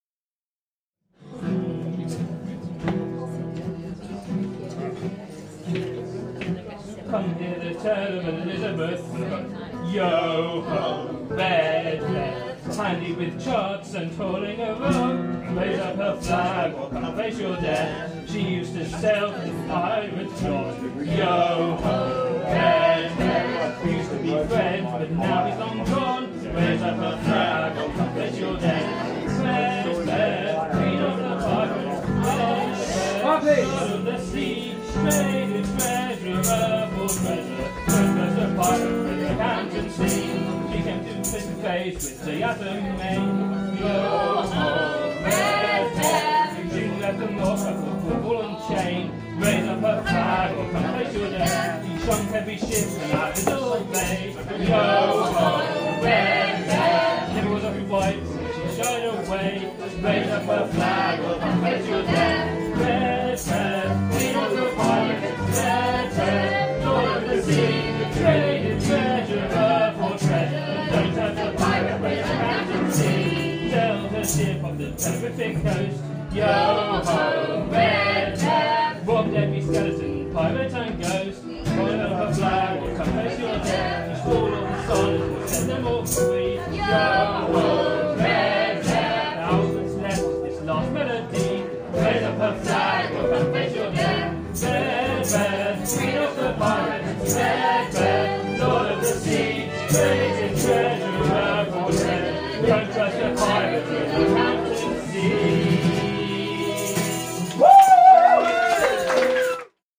A popular shanty can be heard down at the Anathema Arms